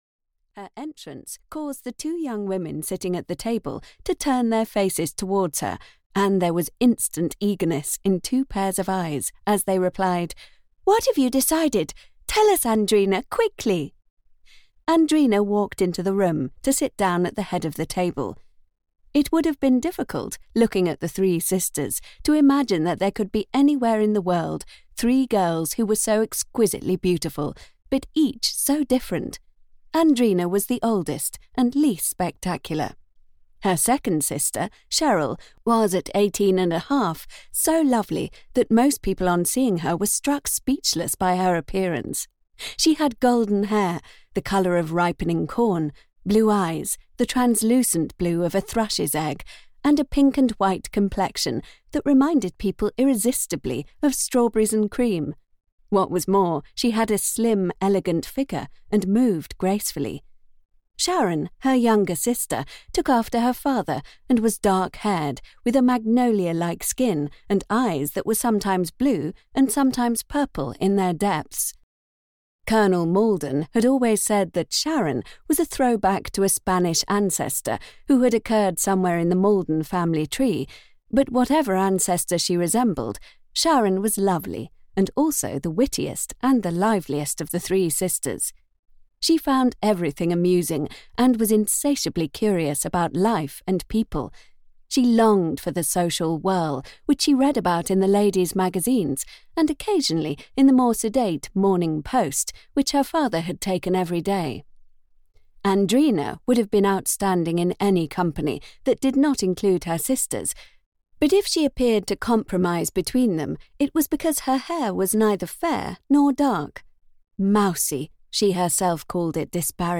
Audiobook The Husband Hunters by Barbara Cartland.
Ukázka z knihy